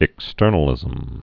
(ĭk-stûrnə-lĭzəm)